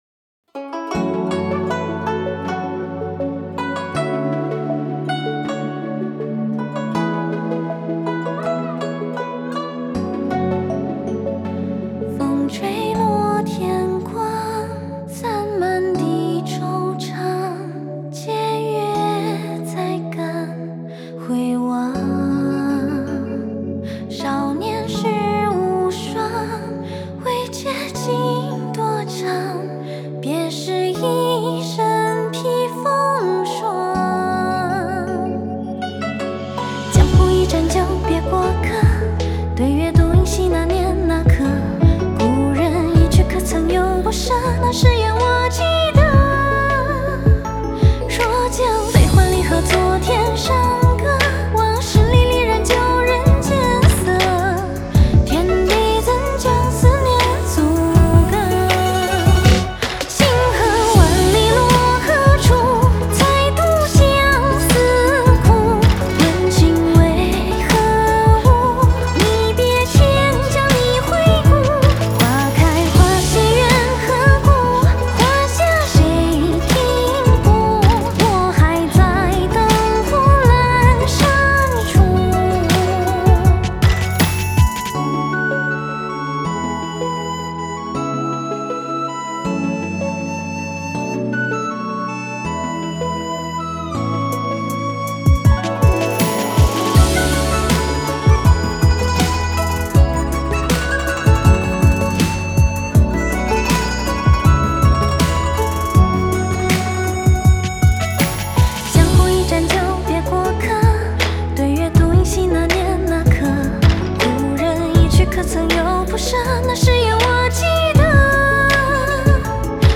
Ps：在线试听为压缩音质节选，体验无损音质请下载完整版
古筝